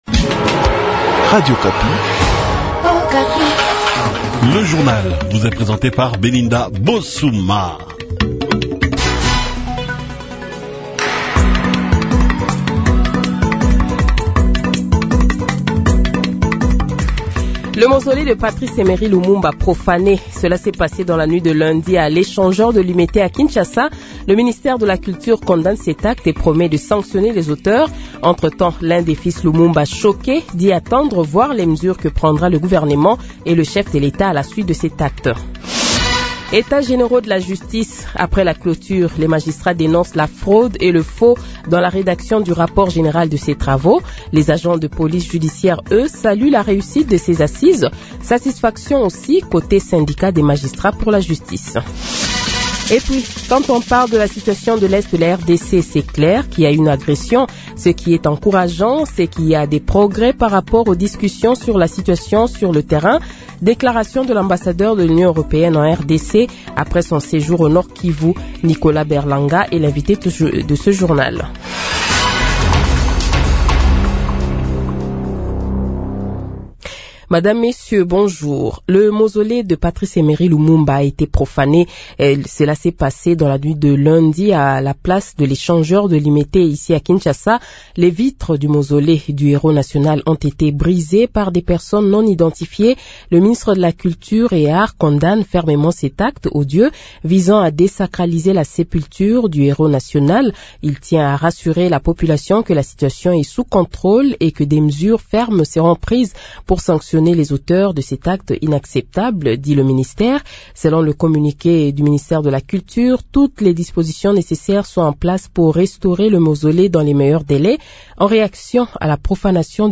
Le Journal de 7h, 20 Novembre 2024 :